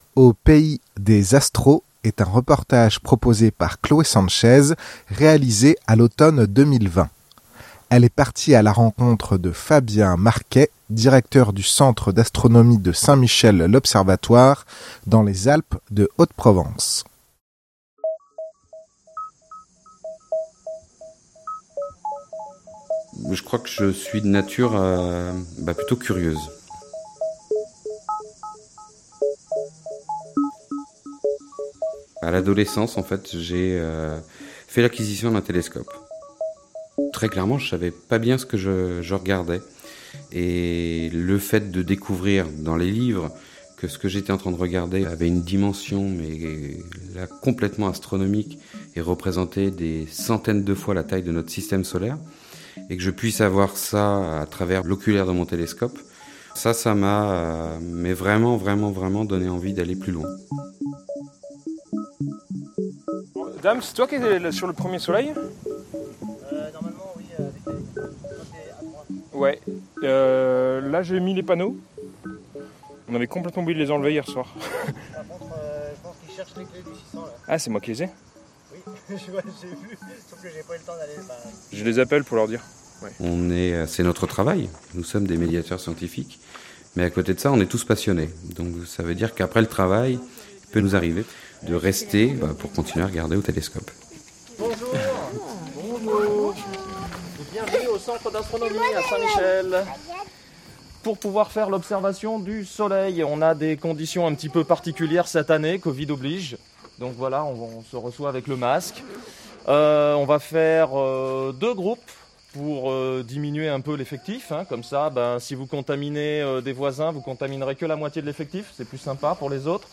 est un reportage